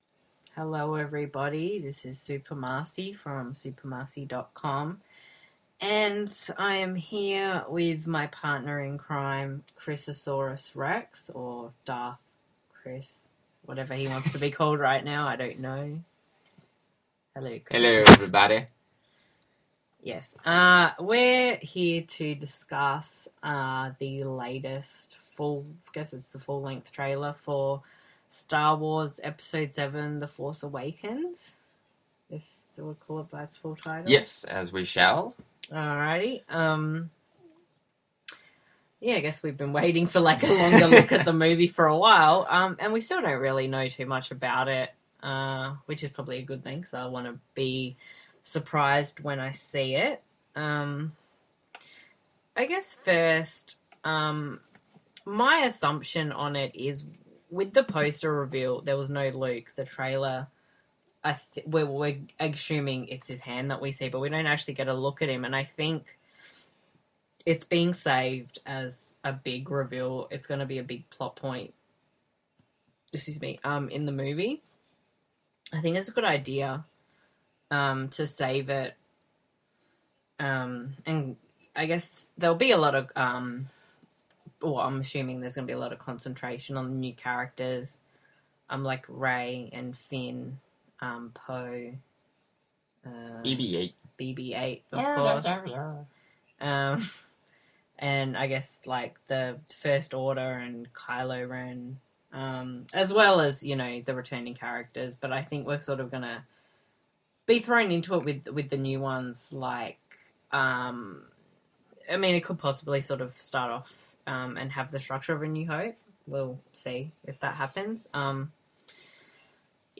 star-wars-the-force-awakens-trailer-discussion.mp3